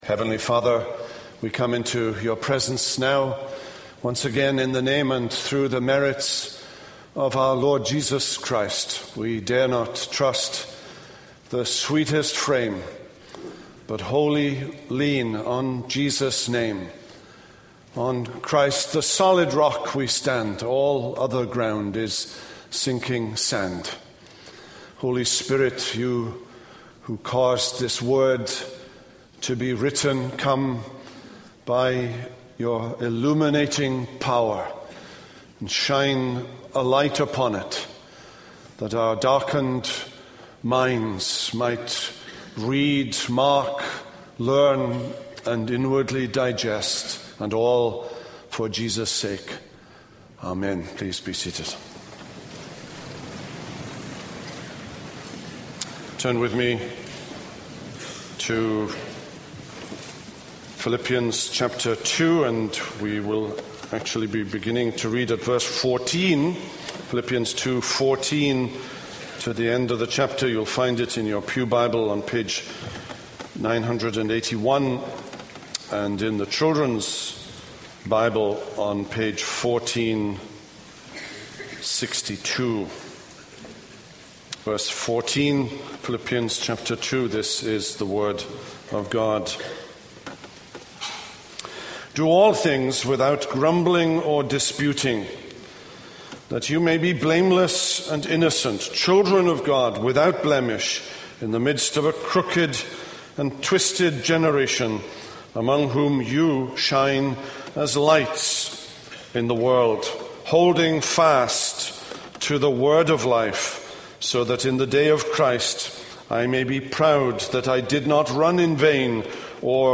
This is a sermon on Philippians 2:19-30.